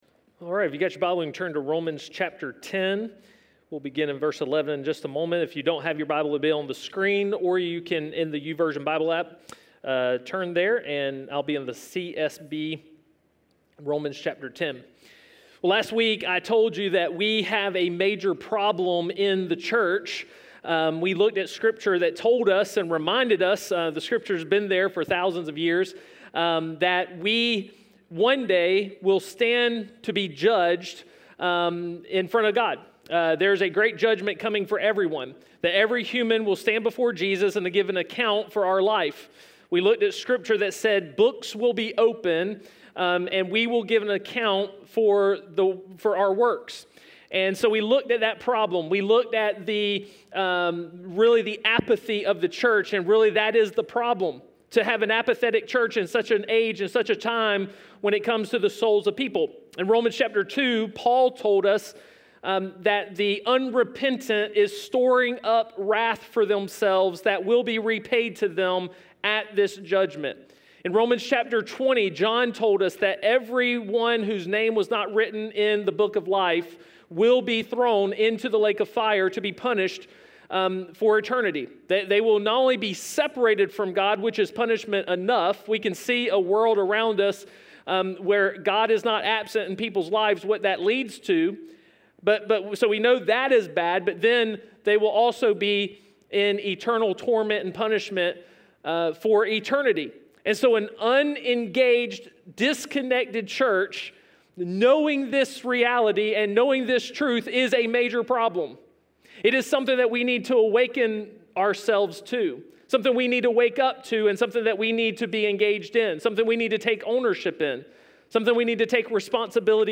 A message from the series "SOLO."